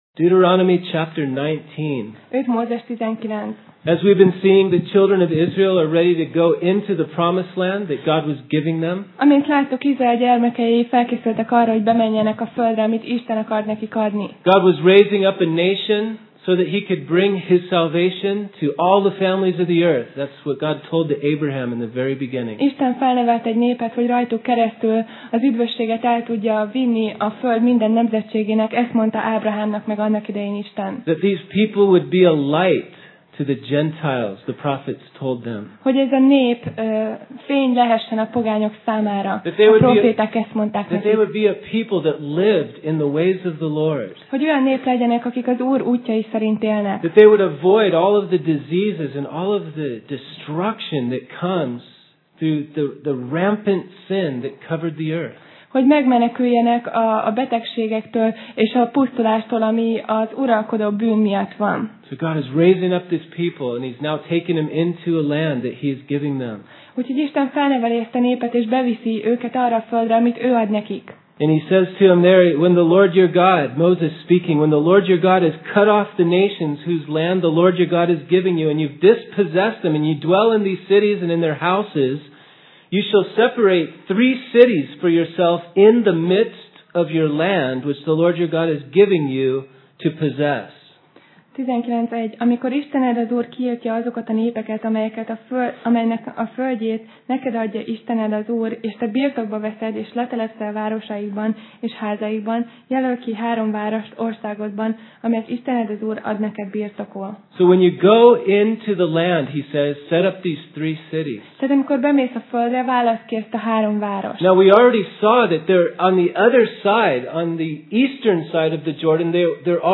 5Mózes Passage: 5Mózes (Deut) 19:1-19 Alkalom: Szerda Este